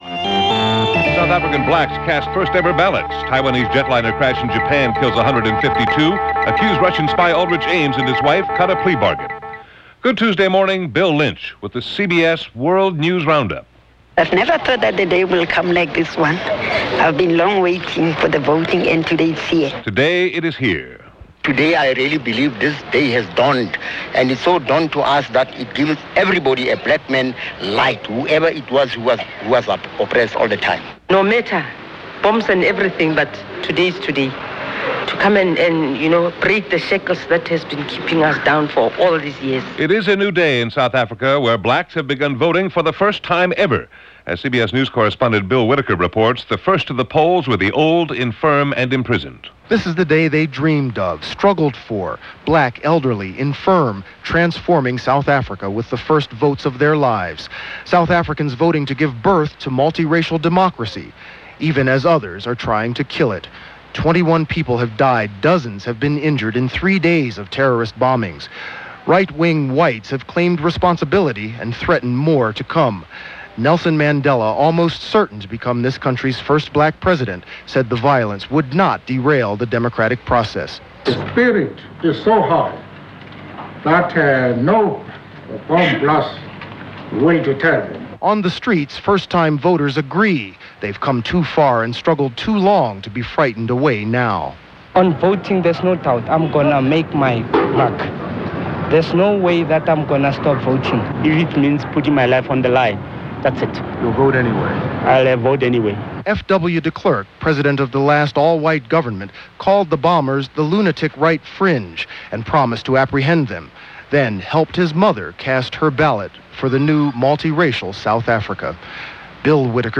And along with the unfolding story in South Africa, that’s just a little of what went on, this April 26, 1994 as reported by The CBS World News Roundup.